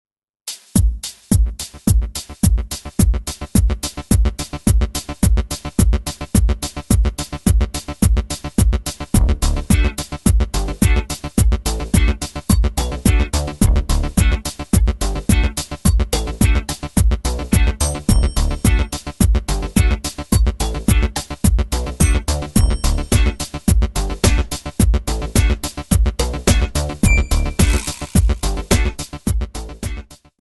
D
MPEG 1 Layer 3 (Stereo)
Backing track Karaoke
Pop, Disco, 1970s